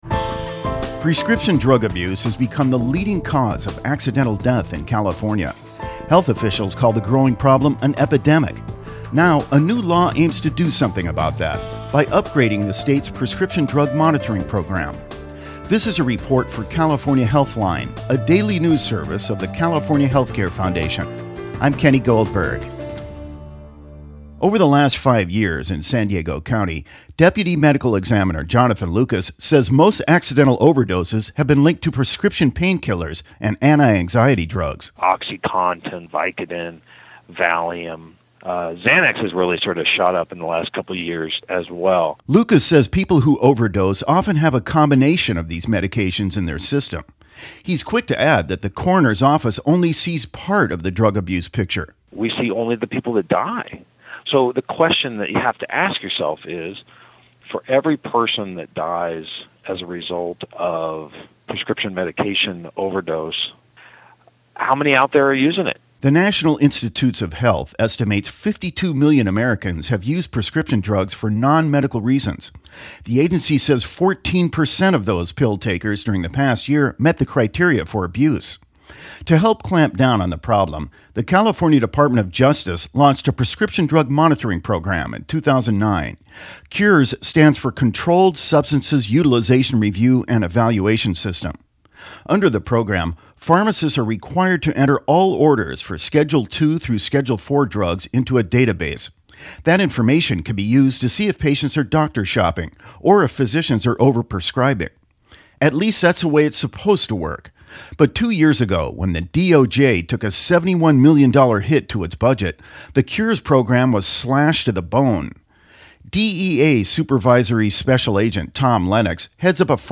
The report includes comments from:
• Sen. Mark DeSaulnier (D-Concord);
Audio Report Insight